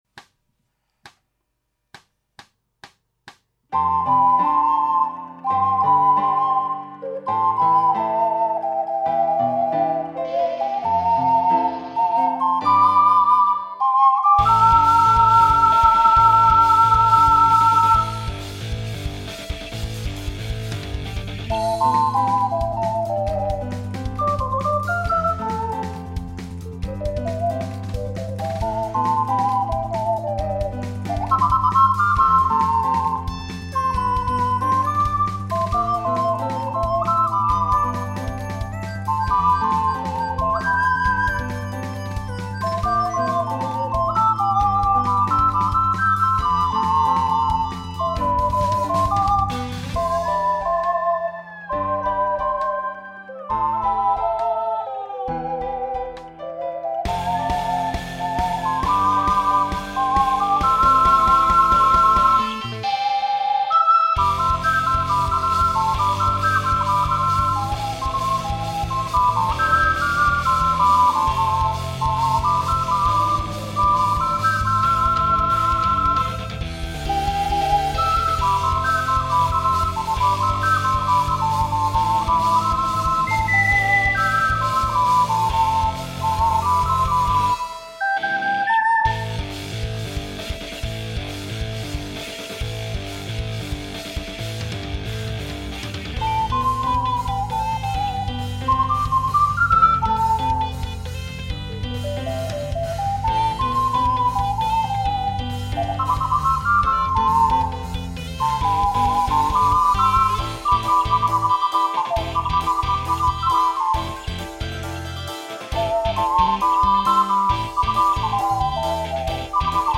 ＊試奏は雑に録音・編集したので、参考程度と思ってください。